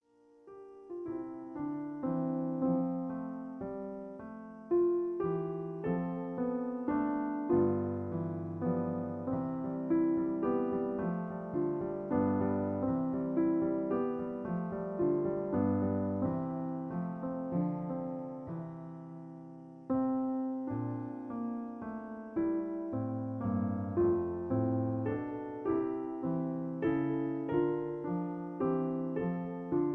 In F. Piano Accompaniment